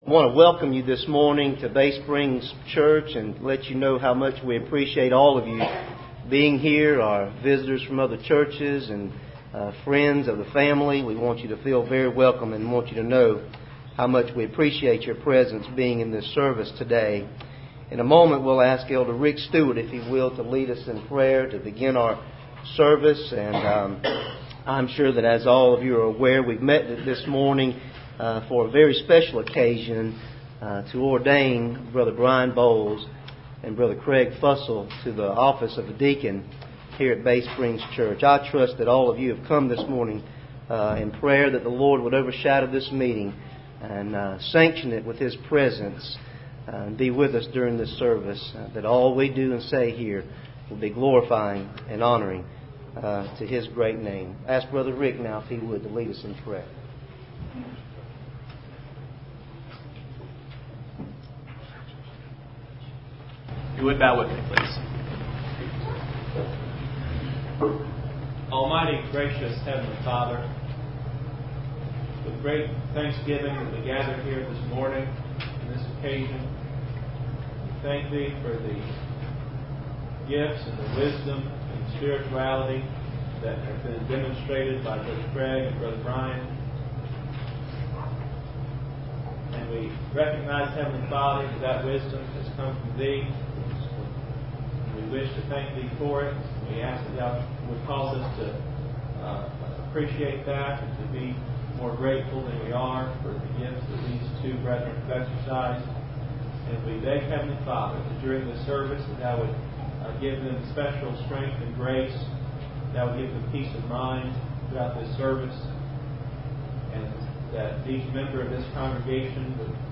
Preacher: Various Ministers Service Type: Ordinations %todo_render% « Commandment Keeping